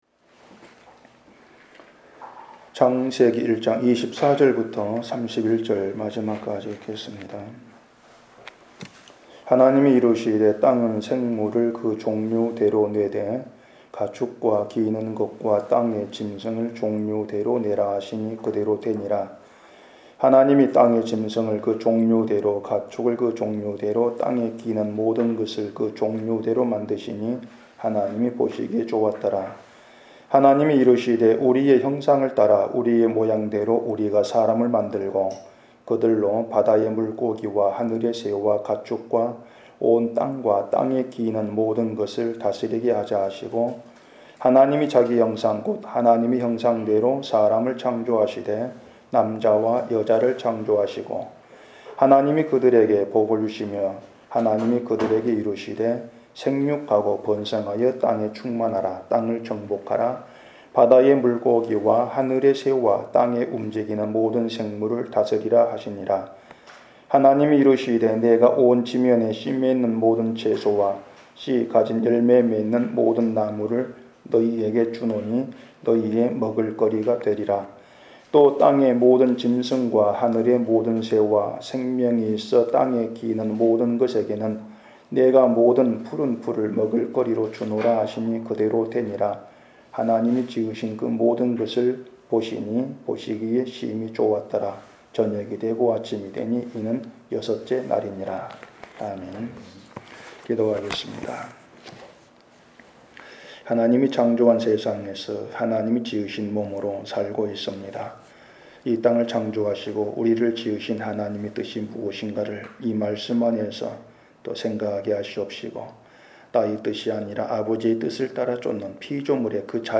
<설교>